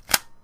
shotgun_put_ammo-3.wav